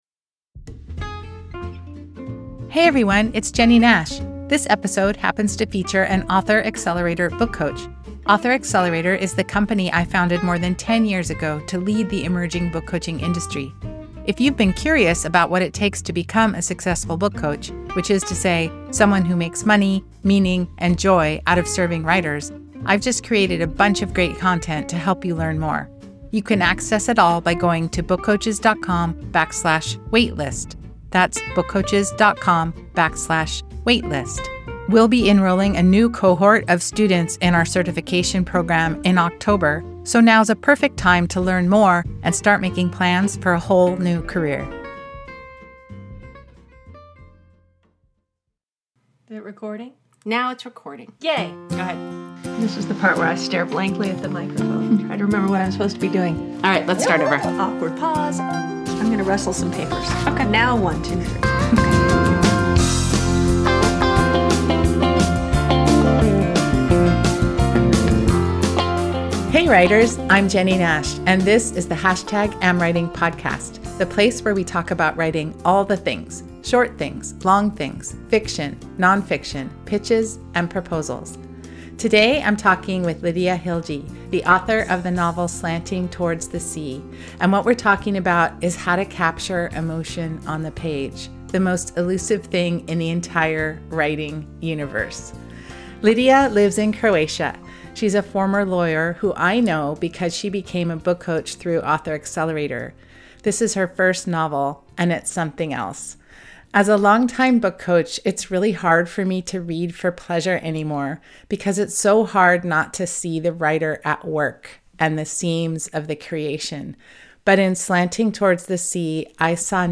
A conversation with debut novelist